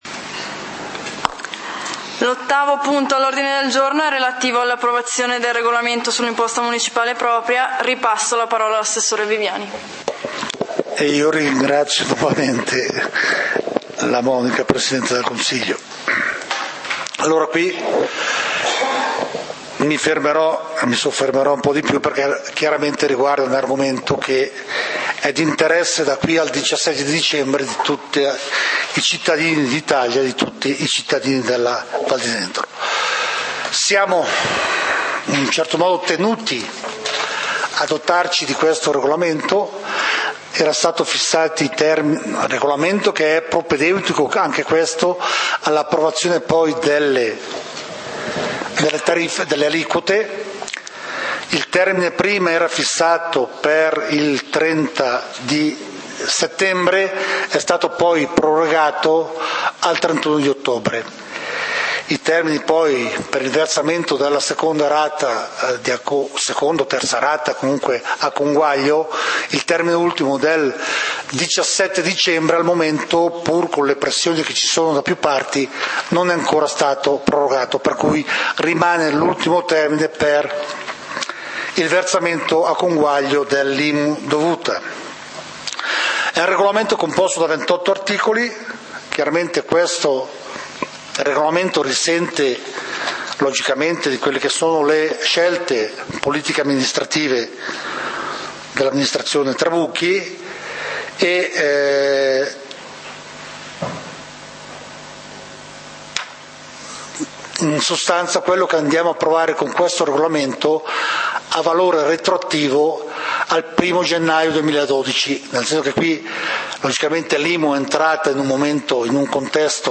Punti del consiglio comunale di Valdidentro del 30 Ottobre 2012